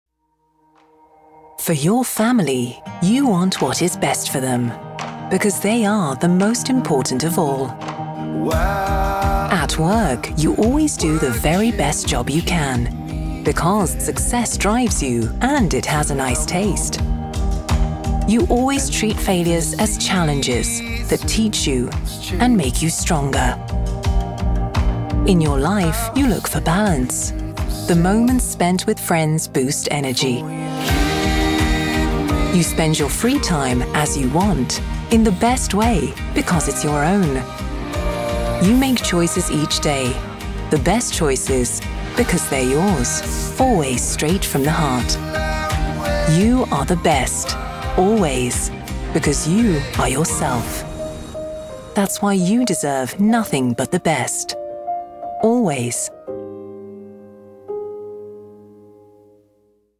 Native speaker Kobieta 30-50 lat
Spot reklamowy